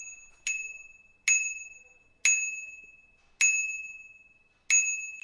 13 G klauzury运动包 " 响铃的自行车圈
描述：响铃自行车铃
标签： 自行车
声道立体声